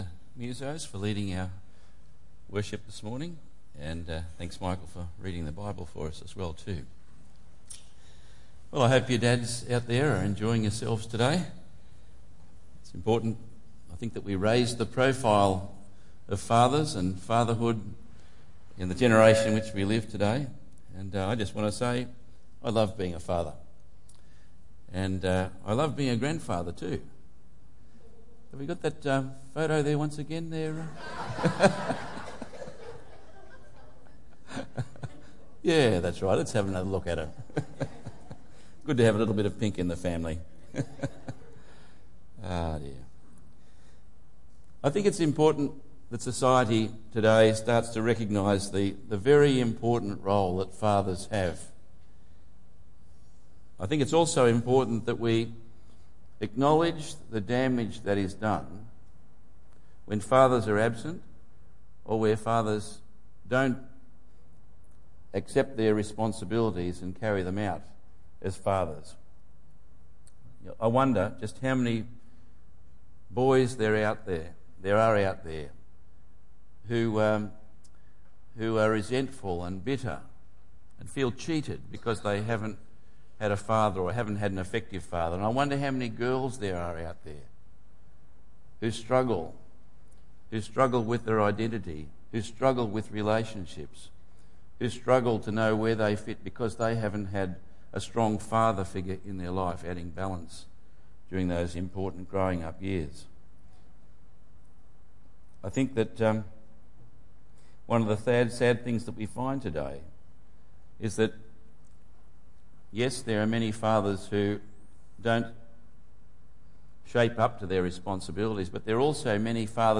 Tagged with Sunday Morning